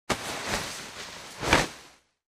На этой странице собраны разнообразные звуки одежды: от шуршания нейлона до щелчков ремней.
Встряхивание вещей